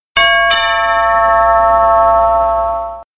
Звуки на звонок